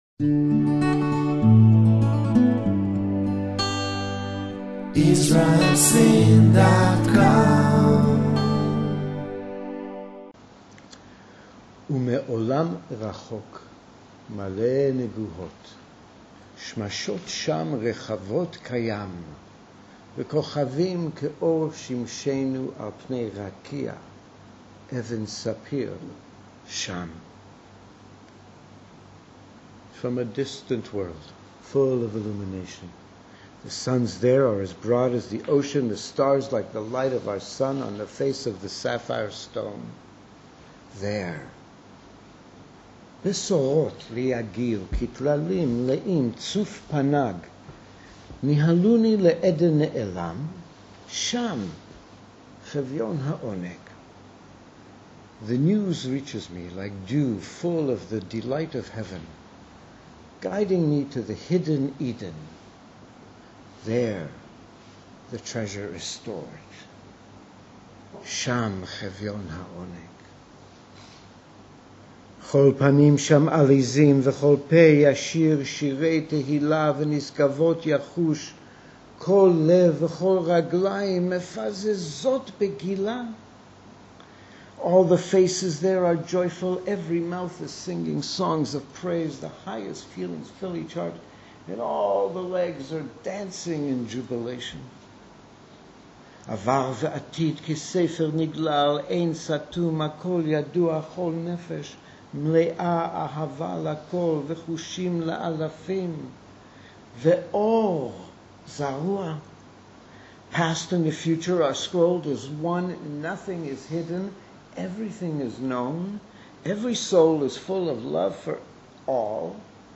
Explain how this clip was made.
Through reflection and discussion, we will explore the significance of these teachings for our personal and interpersonal lives.